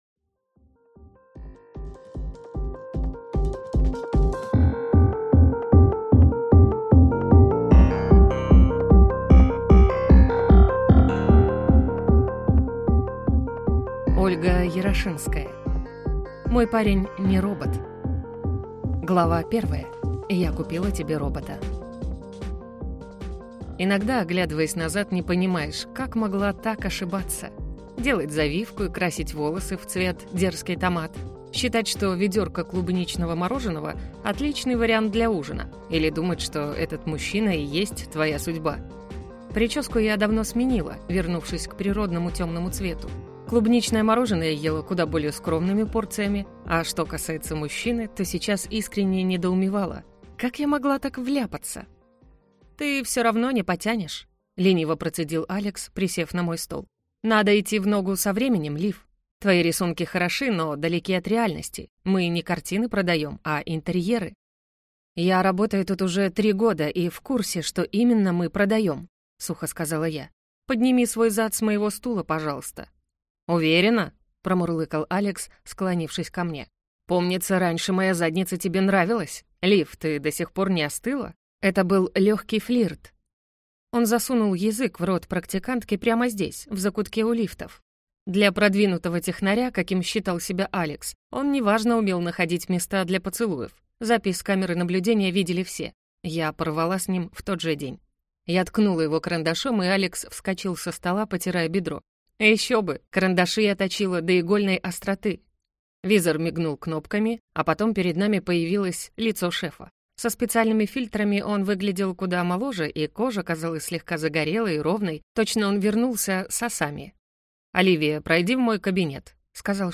Аудиокнига Мой парень (не) робот | Библиотека аудиокниг